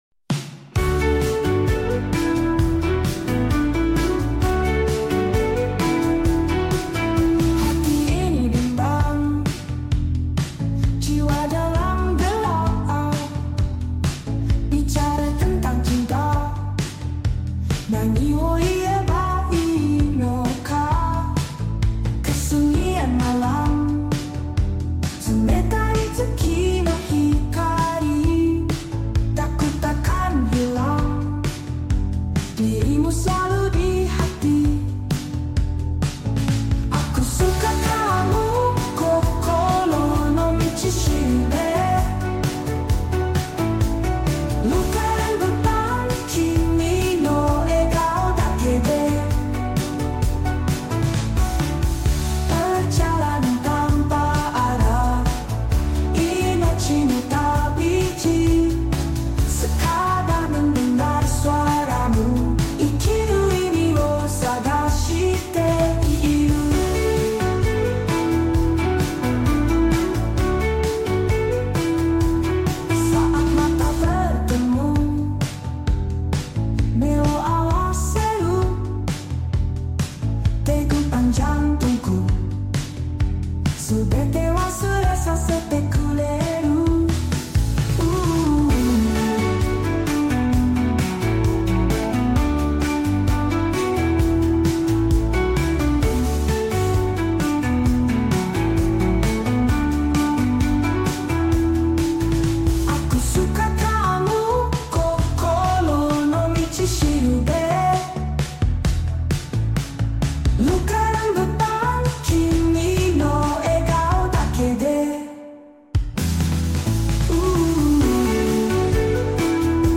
#8dmusic